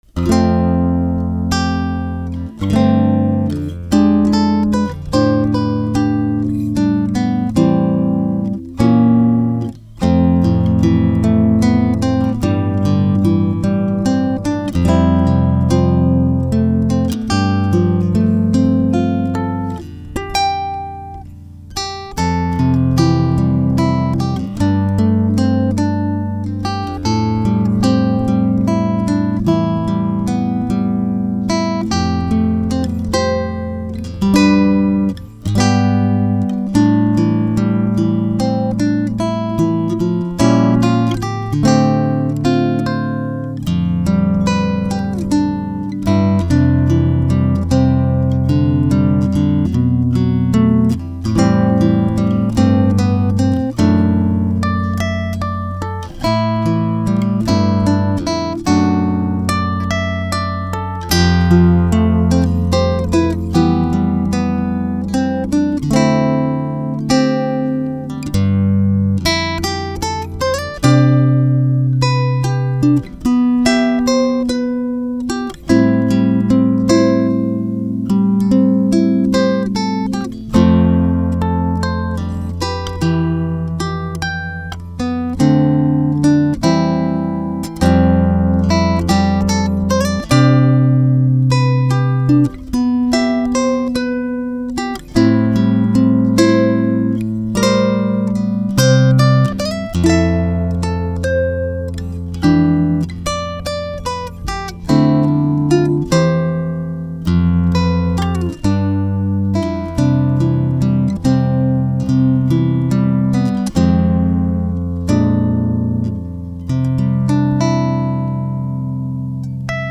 Help with boomy/growling guitar
I am trying to record a fingerstyle piece with my Godin Multiac Encore using a Behringer guitar to USB cable connected to my computer. However, there is this growling sound somewhere around the 100-250hz region.
I have attached the recording done in FL Studio 9 with no adjustments Attachments RAW.mp3 RAW.mp3 4.8 MB · Views: 57